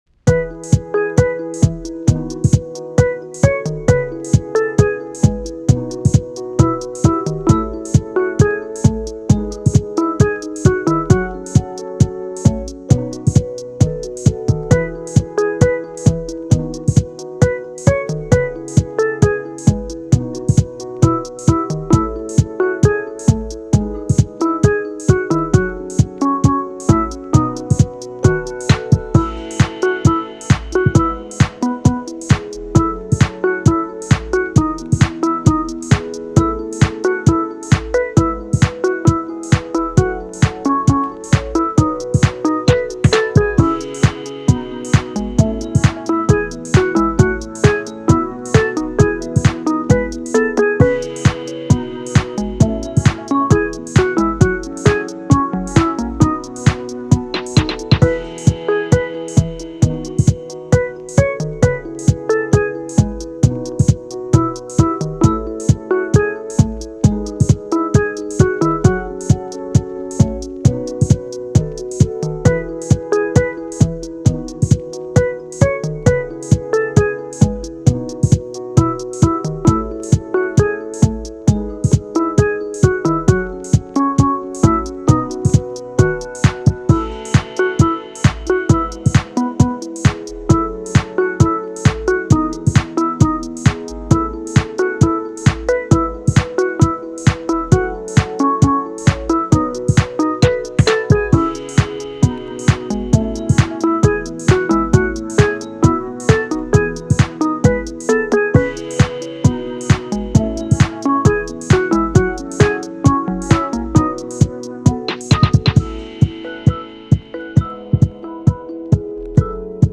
フリーBGM
チル・穏やか
明るい・ポップ
チルポップ , ローファイ , 心地良い author 関連・おすすめ Engine【チルポップ